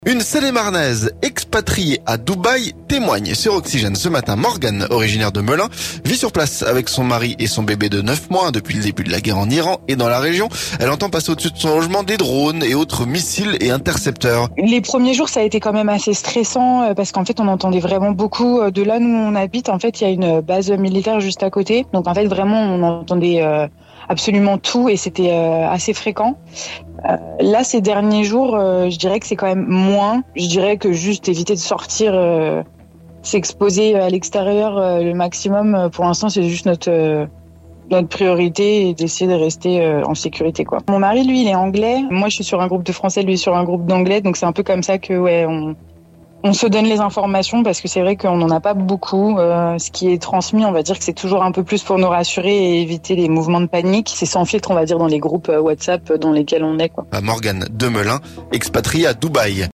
Une Seine-et-marnaise expatriée à Dubaï témoigne sur Oxygène ce matin.